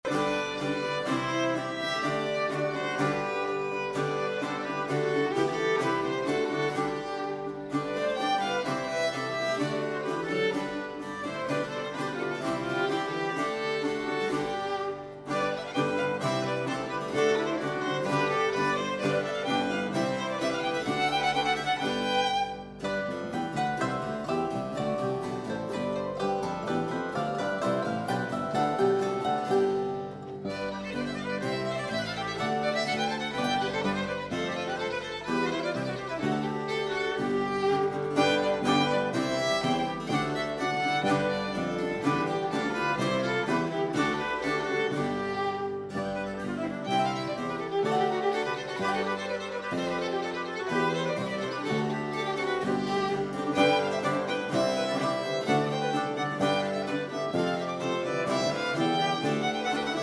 Baroque Ensemble